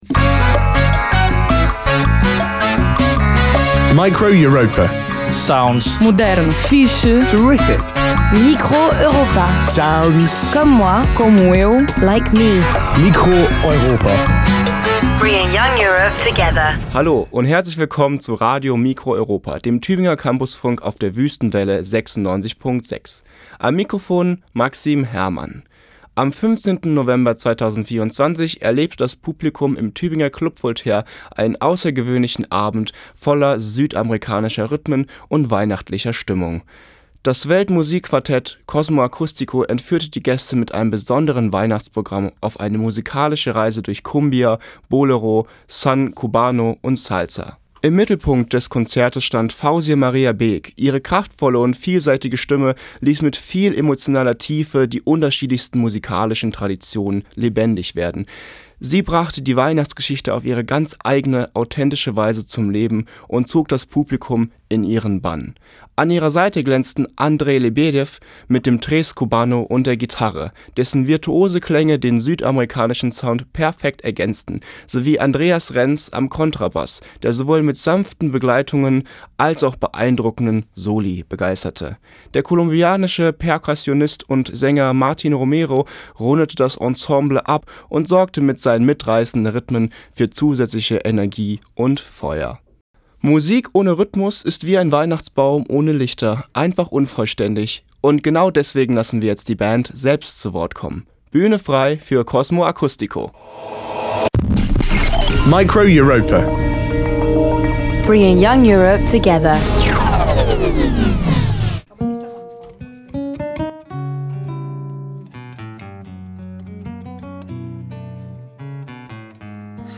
Weltmusik-Quartett
Kontrabass
Gitarre, Tres cubano
Perkussion, Gesang
Live-Aufzeichnung, geschnitten